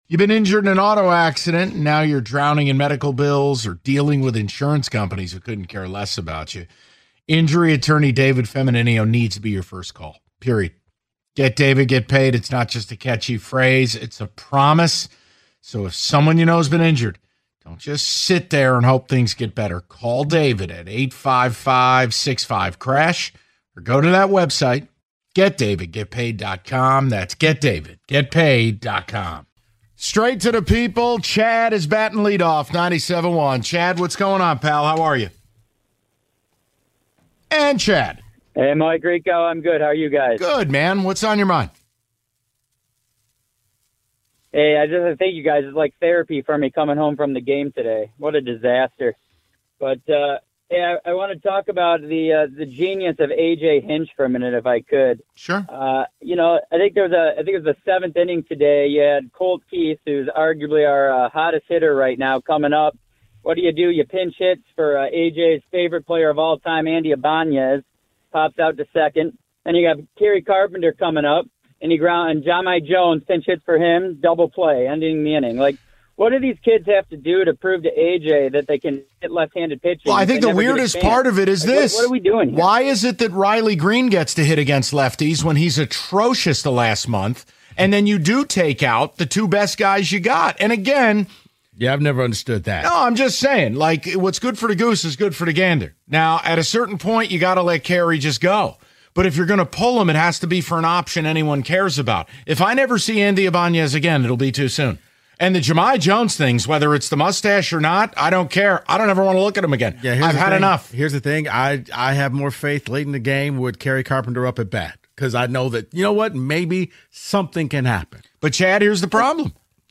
Taking More Of Your Calls On The Tigers